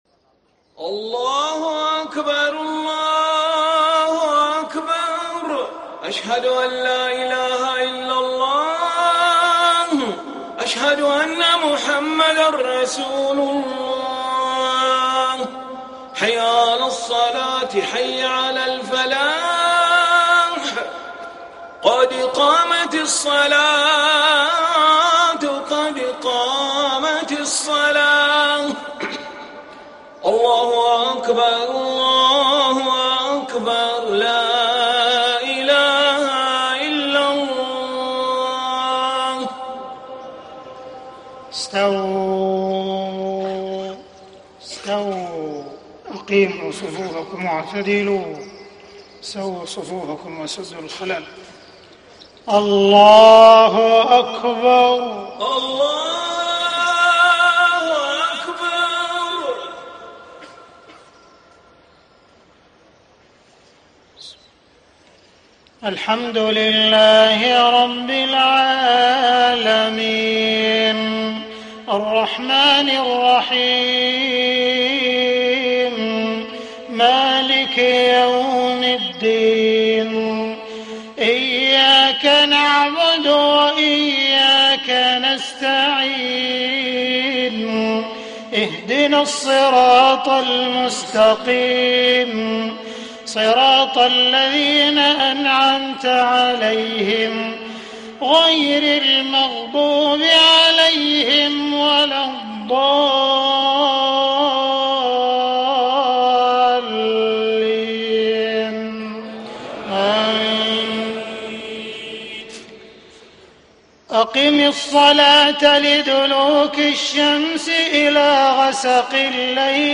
صلاة المغرب 1 - 3 - 1434هـ من سورة الإسراء > 1434 🕋 > الفروض - تلاوات الحرمين